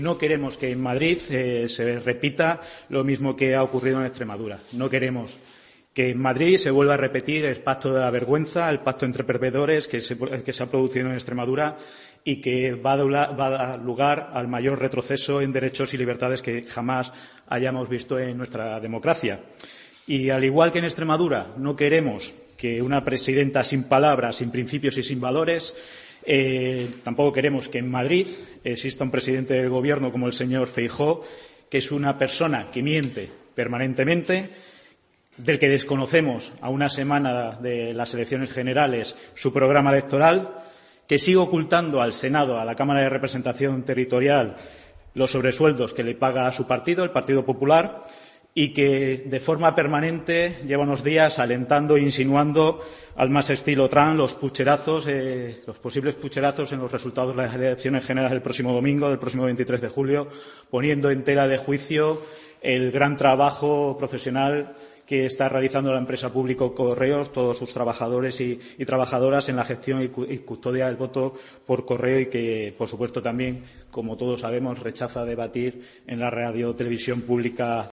El candidato del PSOE al Senado Rafael Lemus, en rueda de prensa -